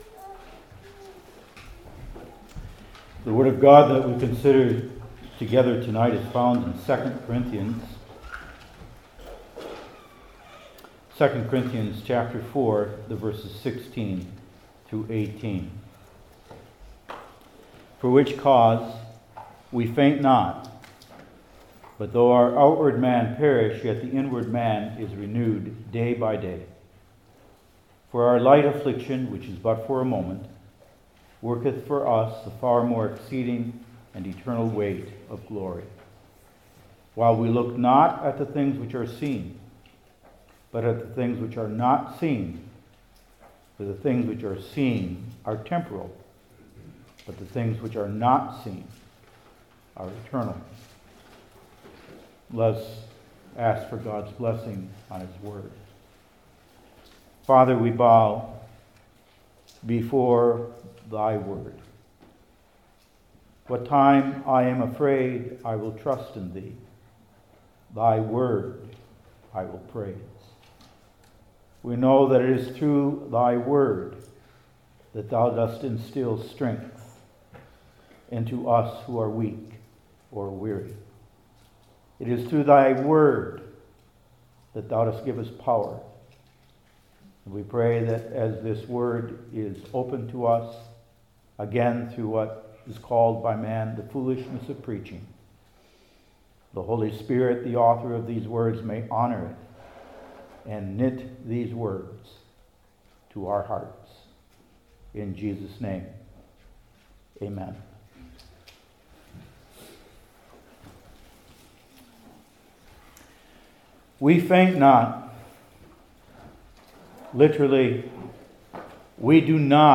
New Testament Individual Sermons I. A Bold Declaration II.